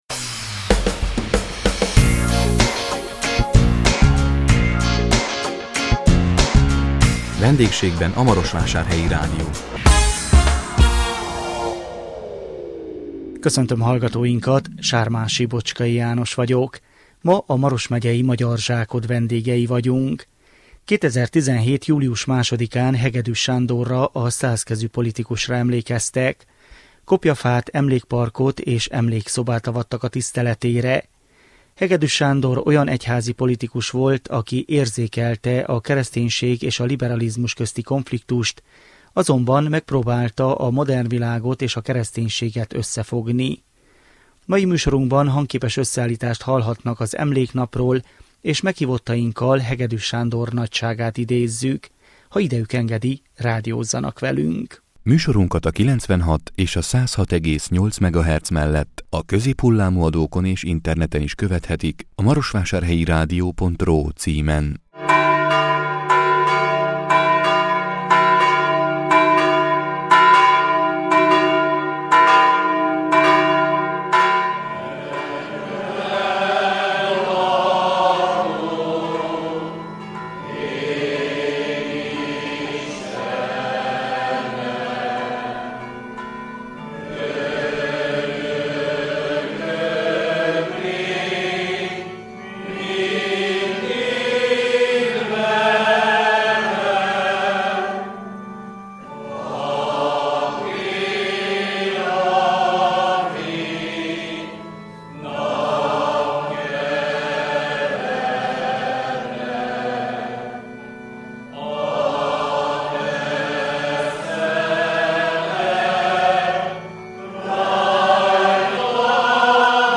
A 2017 november 2-án jelentkező Vendégségben a Marosvásárhelyi Rádió című műsorunkban a Maros megyei Magyarzsákod vendégei voltunk. 2017 július 2-án Hegedüs Sándorra, a százkezű politikusra emlékeztek.
Hegedüs Sándor olyan egyházi politikus volt, aki érzékelte a kereszténység és a liberalizmus közti konfliktust, azonban megpróbálta a modern világot és a kereszténységet összefogni. Műsorunkban hangképes összeállítást hallhattak az emléknapról és meghívottainkkal Hegedüs Sándor nagyságát idéztük.